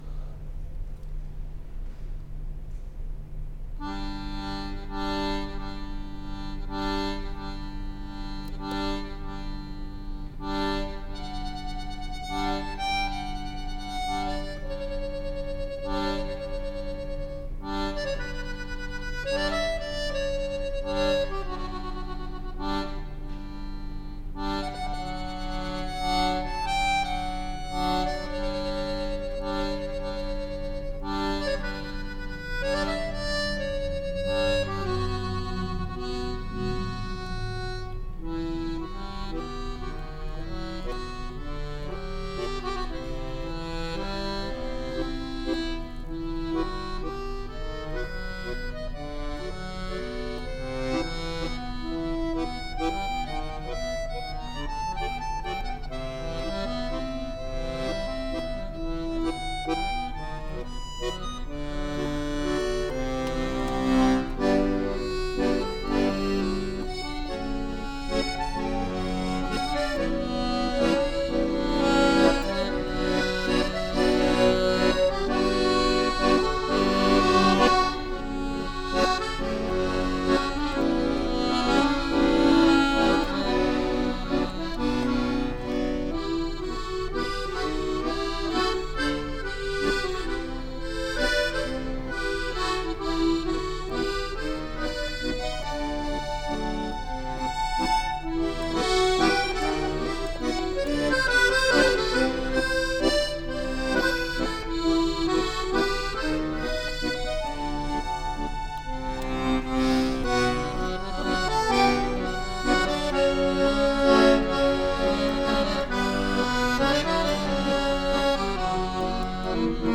LA that plays original chamber music for accordion.